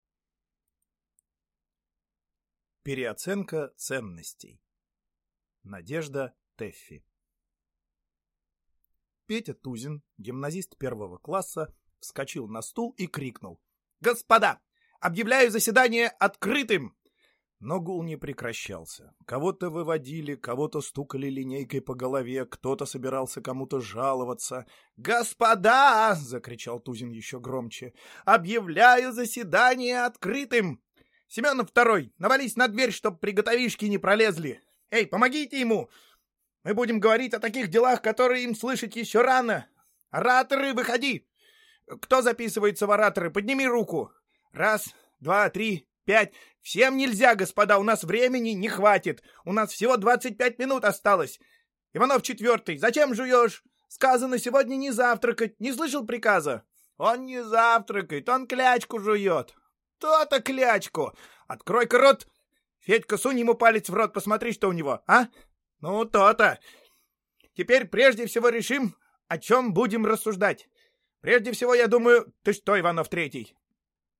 Аудиокнига Переоценка ценностей | Библиотека аудиокниг